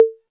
808-Cowbell4.wav